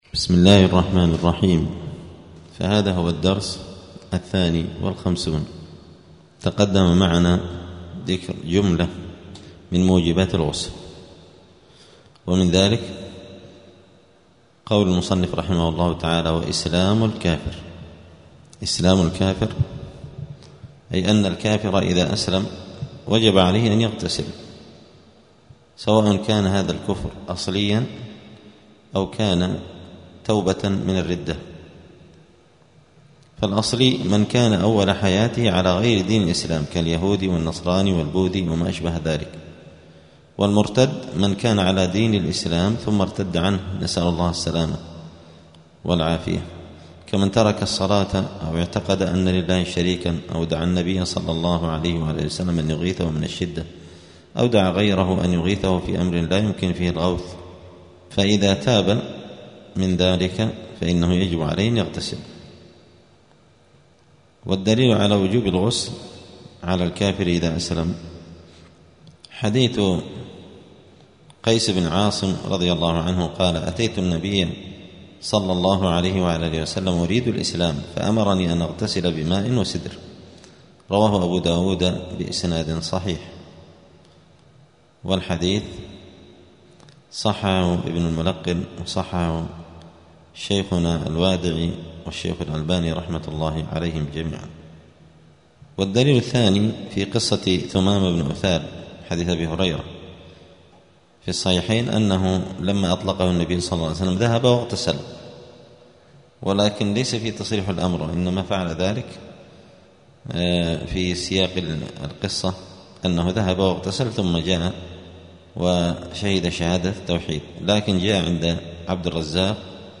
*الدرس الثالث والخمسون (53) {كتاب الطهارة باب موجبات الغسل وصفته غسل جميع البدن في الجنابة}*